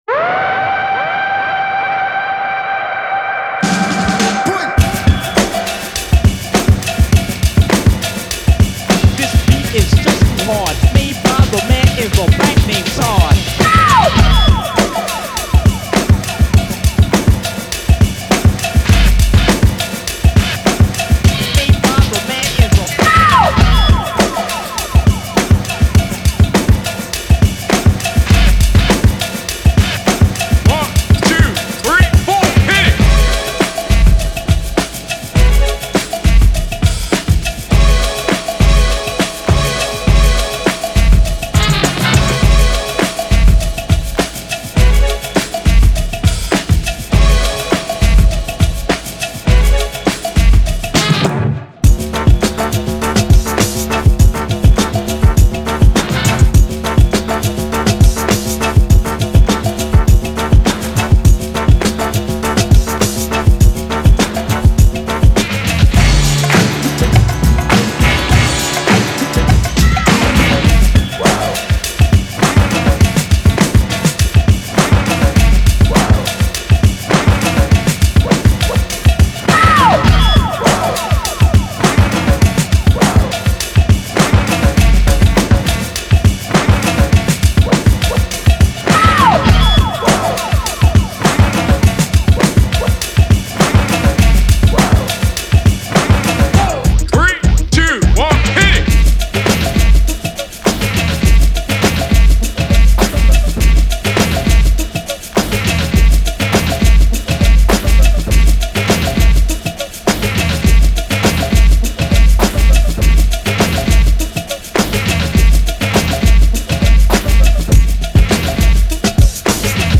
draws inspiration from the funky vibes